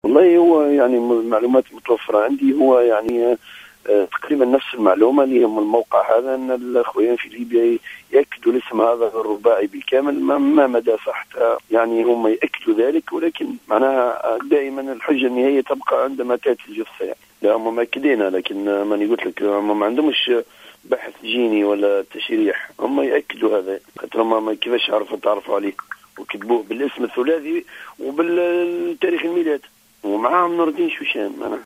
تصريح هاتفي